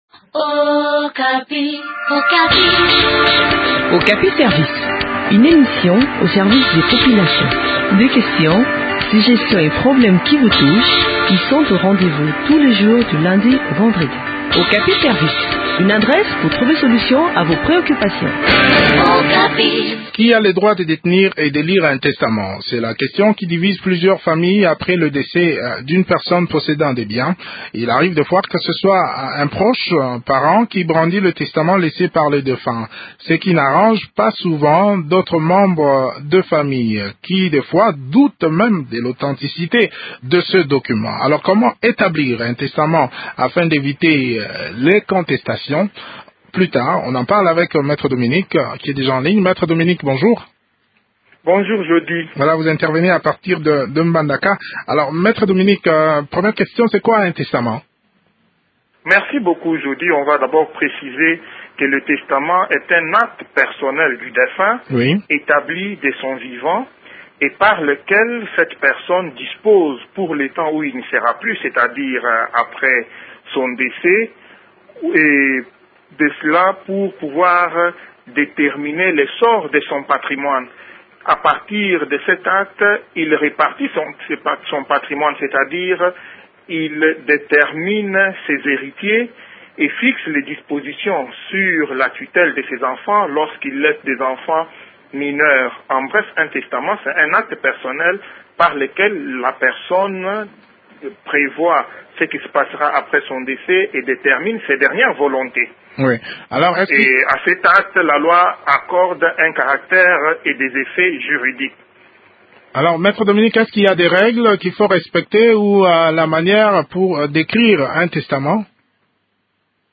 Comment rédiger un testament ? Quelles sont les formes de testaments ? Réponses dans cet entretien